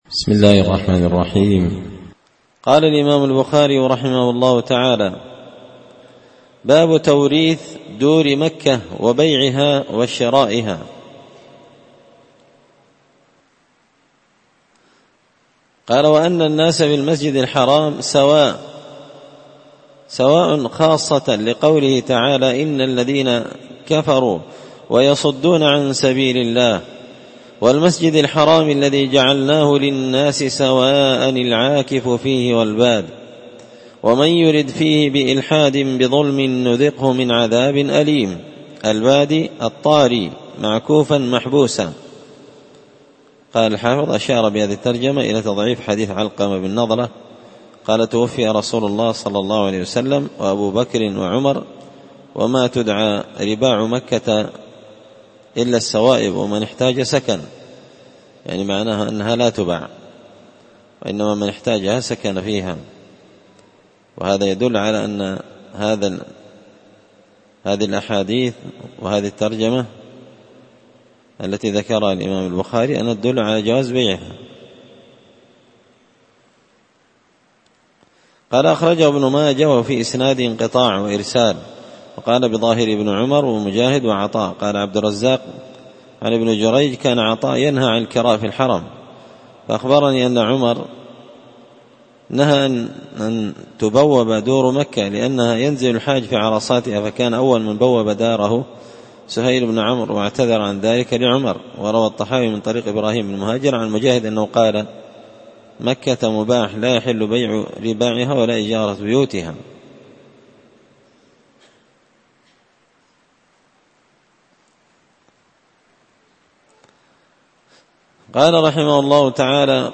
كتاب الحج من شرح صحيح البخاري – الدرس 39
دار الحديث بمسجد الفرقان ـ قشن ـ المهرة ـ اليمن